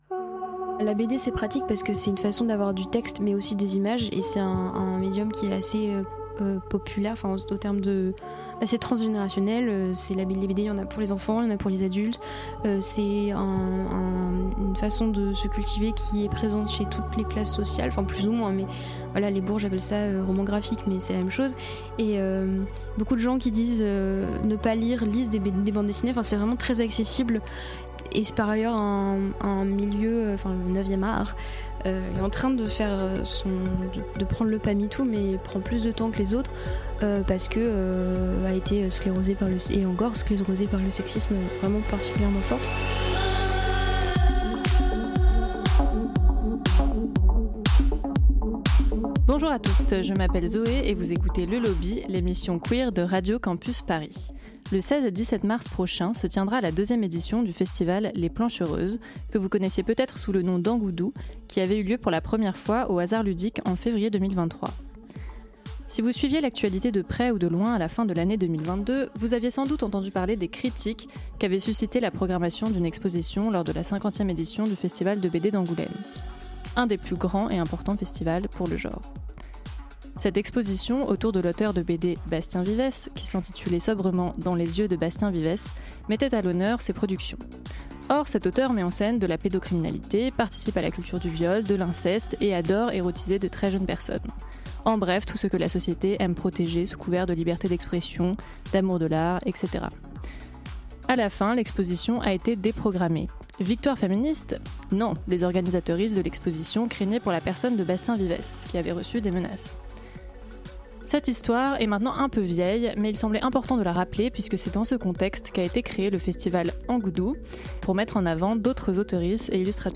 Les 16 et 17 mars prochain, le festival Plancheur.euses met à l'honneur la bande-dessinée LGBT+ aux Arches Citoyennes. Rencontre avec deux des organisateurices de l'événement.